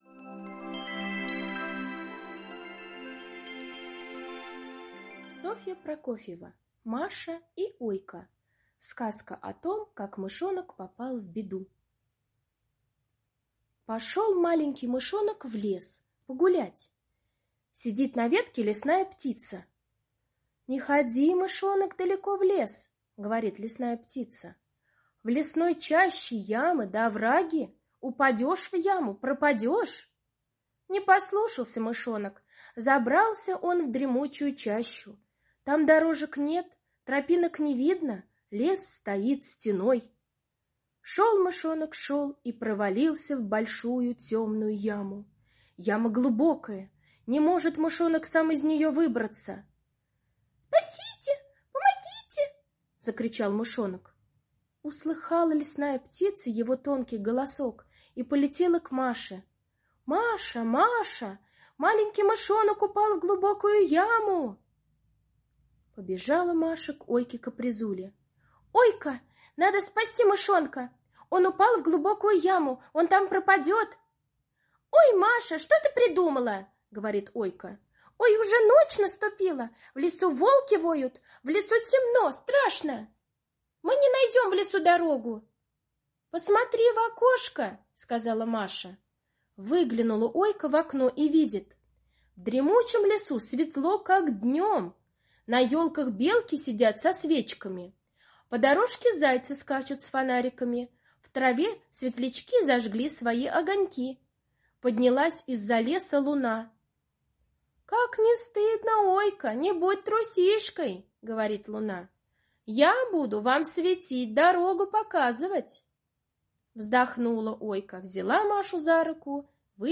Сказка о том как мышонок попал в беду - аудиосказка Прокофьевой С. История о том, как Маша и Ойка спасали Мышонка.